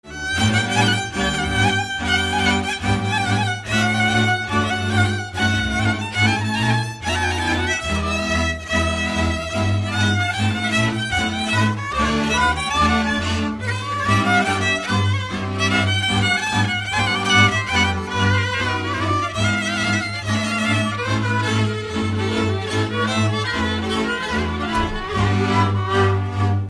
Dallampélda: Hangszeres felvétel
Erdély - Kolozs vm. - Magyarszovát
hegedű
kontra (háromhúros)
bőgő
Műfaj: Hétlépés
Stílus: 2. Ereszkedő dúr dallamok